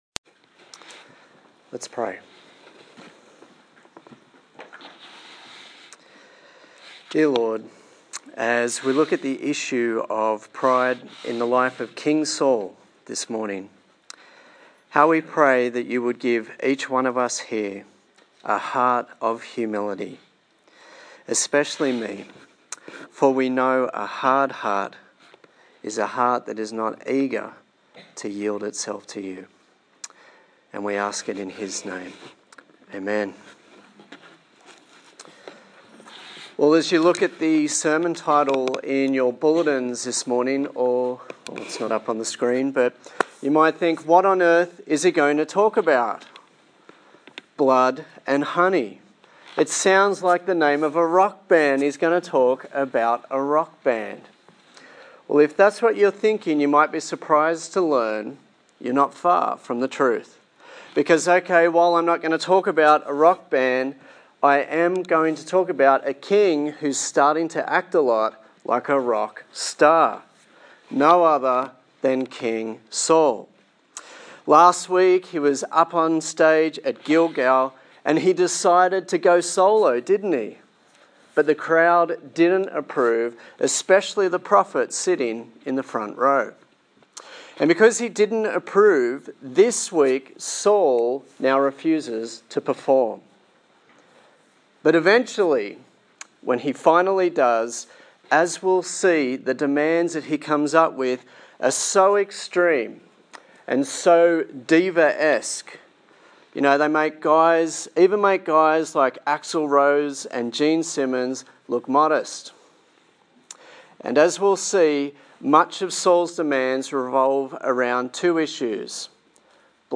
1 Samuel Passage: 1 Samuel 14 Service Type: Sunday Morning